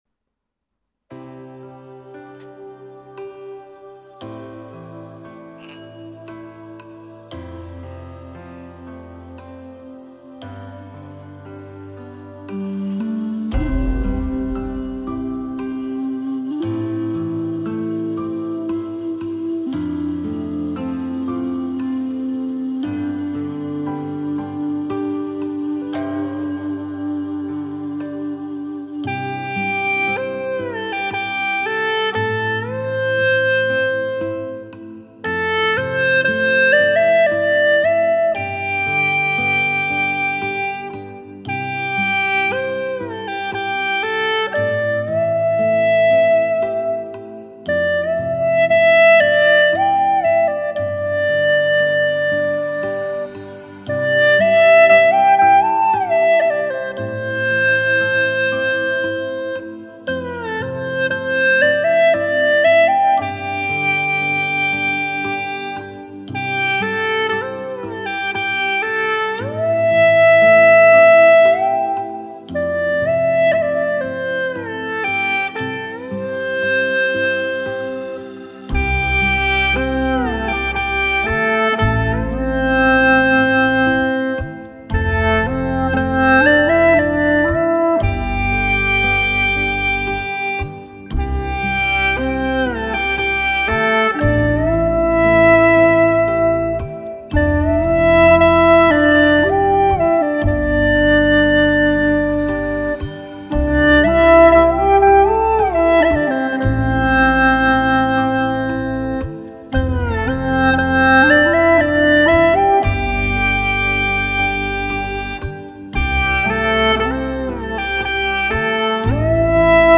调式 : C 曲类 : 流行
缓缓的旋律，非常少的技巧